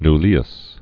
prelate nul·li·us
(n-lēəs)